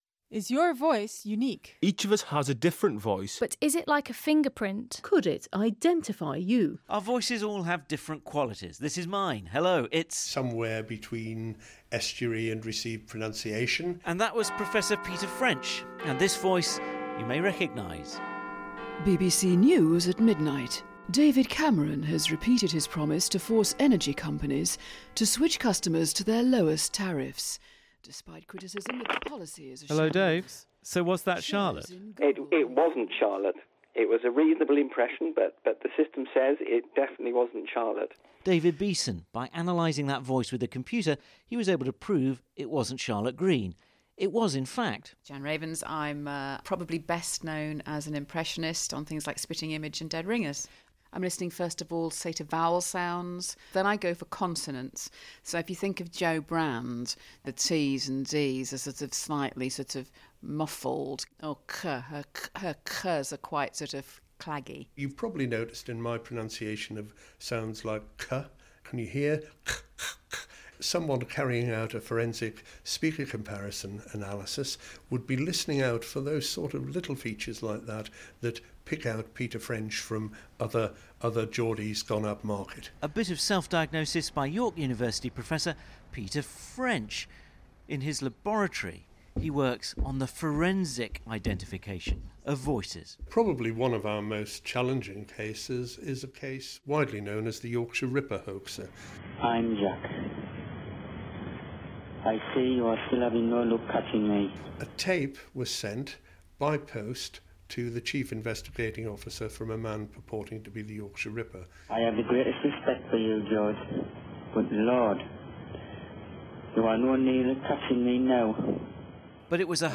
A short radio piece (shortened further for Audioboo) on voice identification...contains voices.